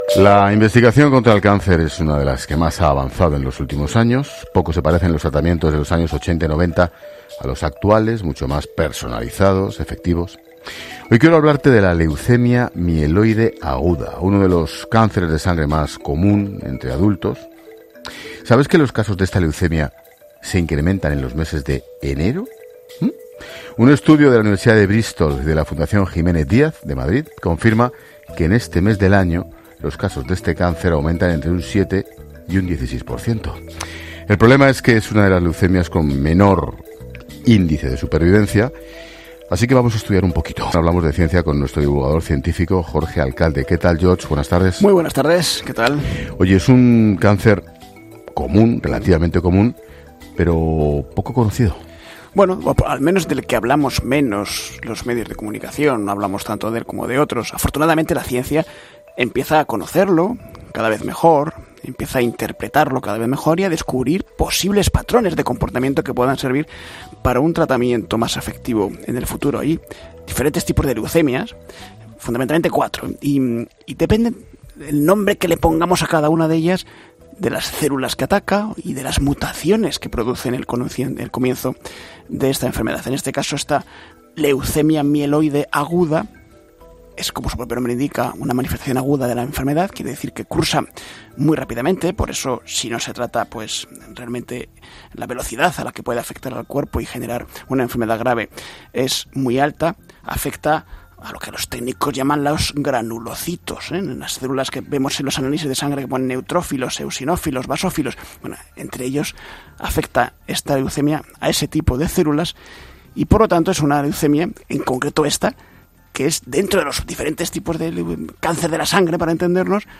Con Ángel Expósito